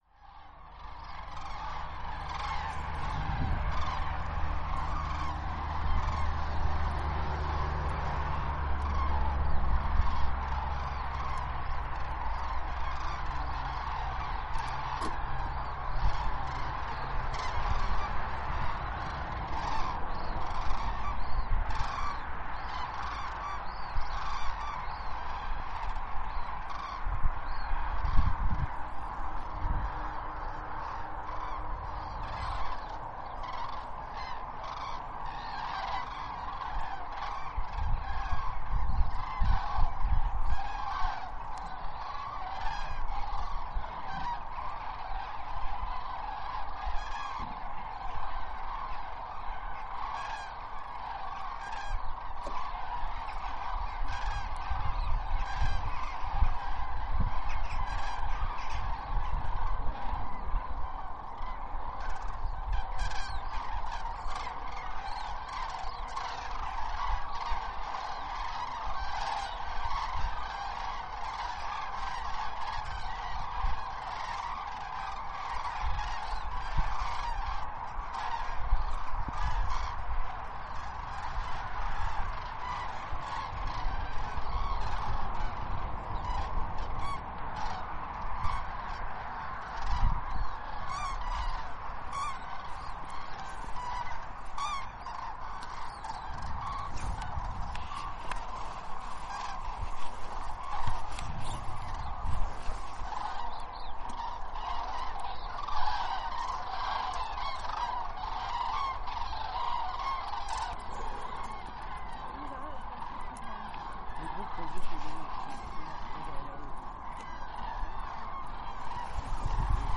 Grue cendrée (Grus grus)
Lac du Der (Haute-Marne), les 25 et 26/10/2022.
Le réveil des grues.